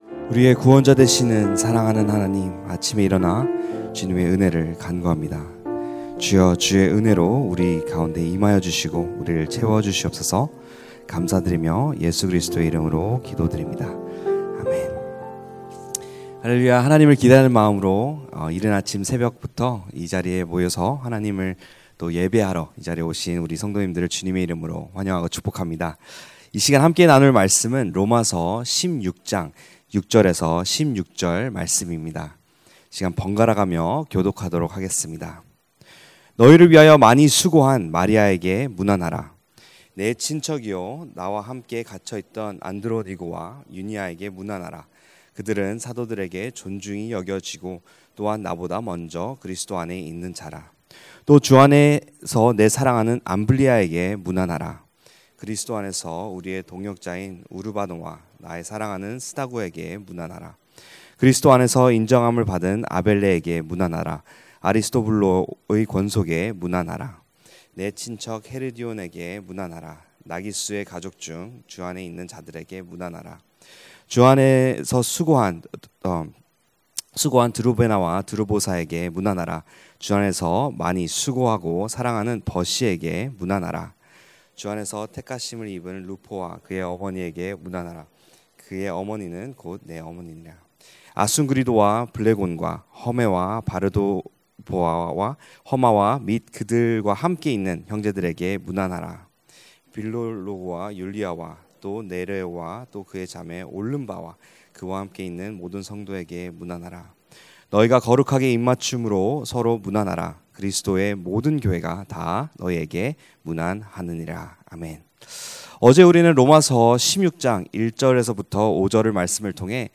2025-08-21 새벽기도회 (롬 16:6-16) 주 안에서 문안하라
> 설교